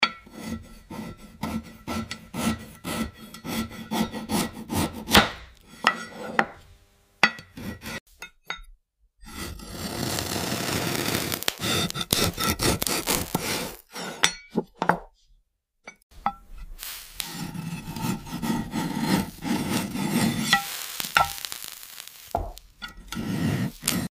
AI Cutting | Rusted Objects Sound Effects Free Download
Cutting old rusted objects.